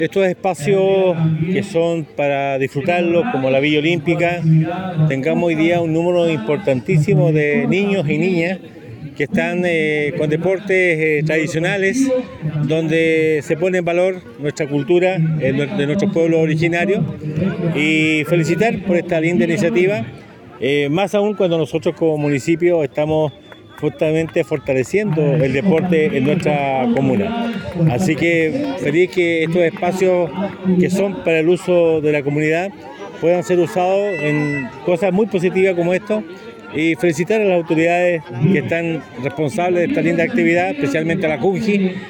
Por último el Alcalde de Osorno, Emeterio Carrillo, destacó el uso de los diversos espacios que tiene la comuna, para poner en valor la cultura de nuestros pueblos originarios.